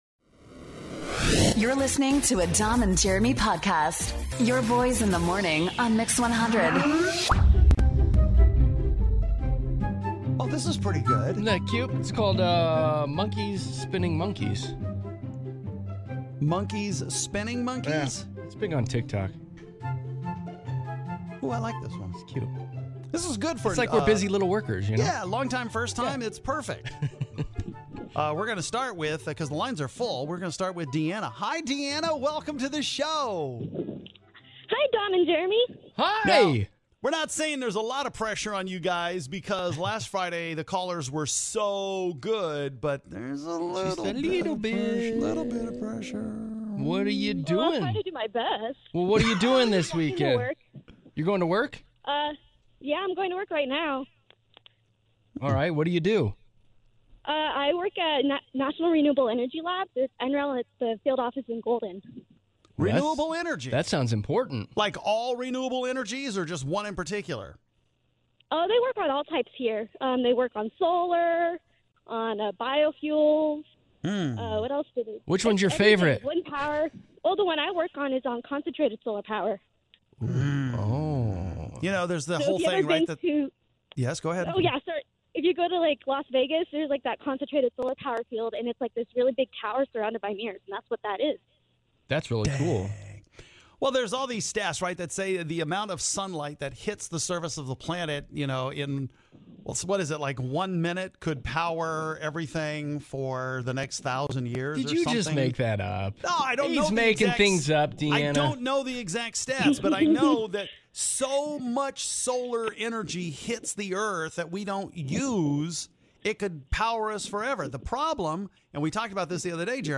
We talk to our longtime listeners here that are first time callers.